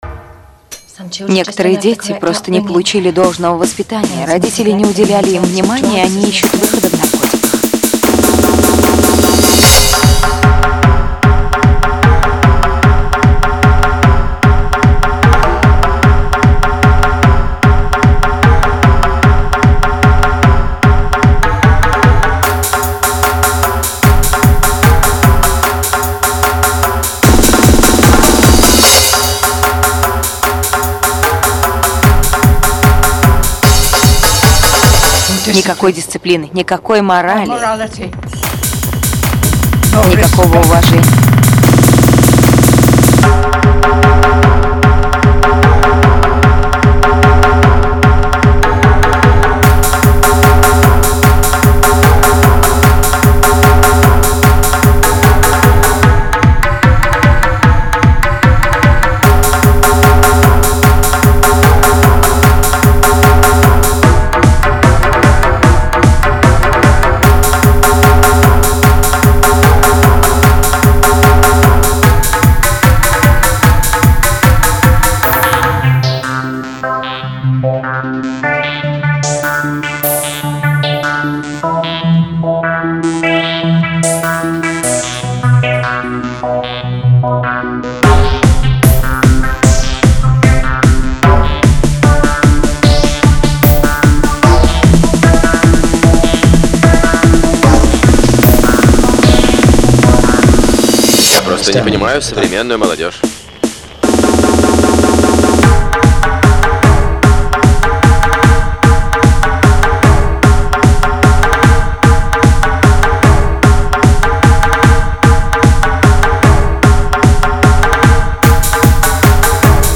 hard_bass___dolbeshka_z2_fm.mp3